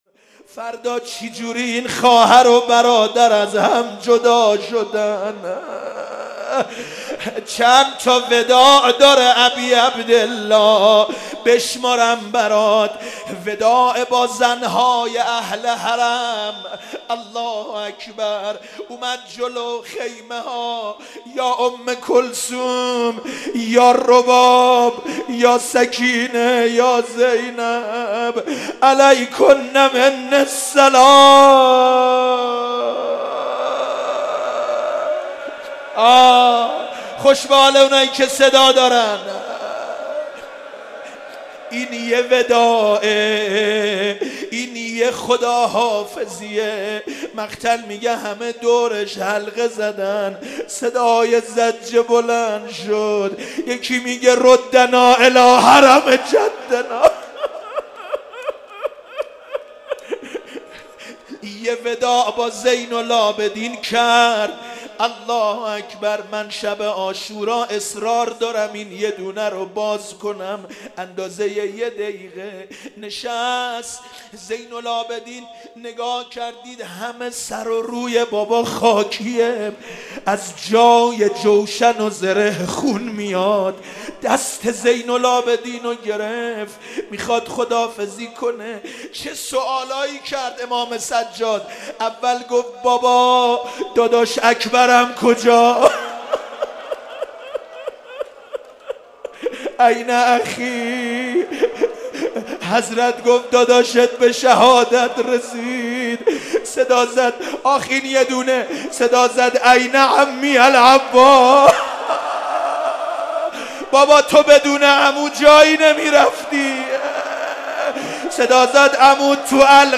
شب عاشورا محرم94_روضه وداع اباعبدالله